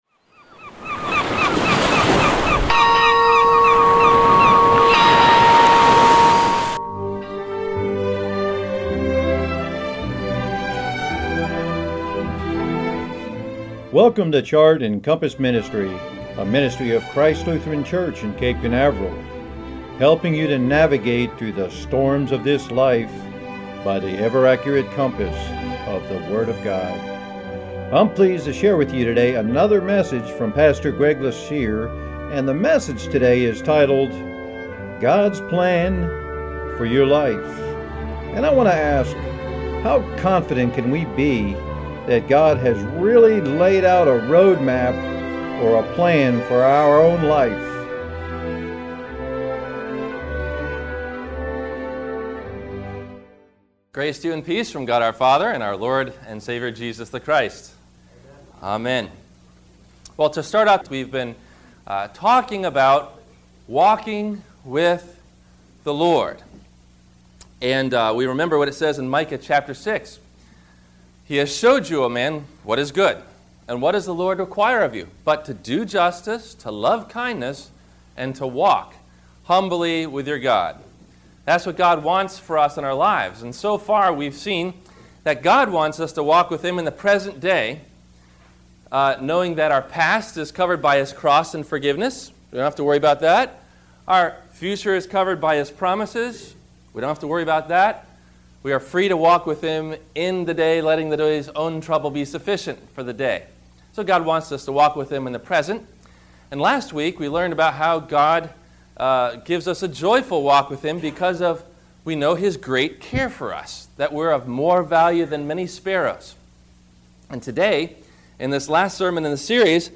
Do You Have a Sense of Urgency about Christ’s Second Coming? – WMIE Radio Sermon – July 06 2015